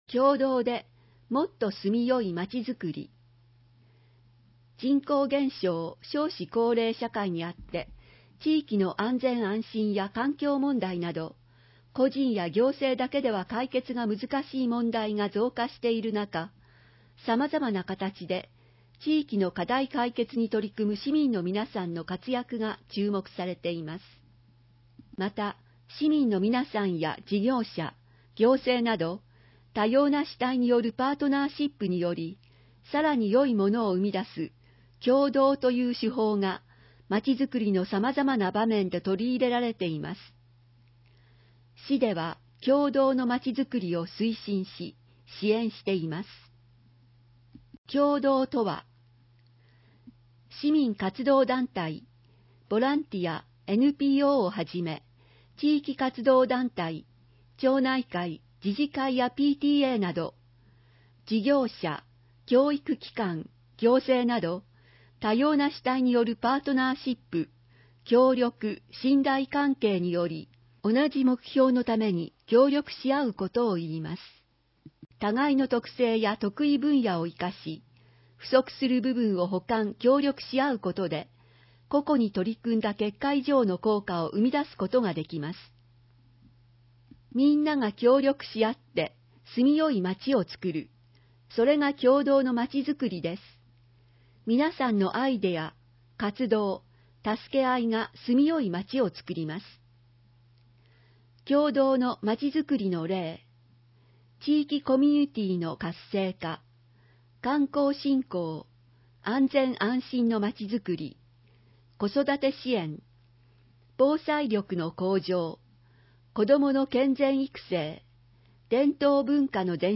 音声は、ボランティアグループ「やまびこの会」が朗読録音したものです。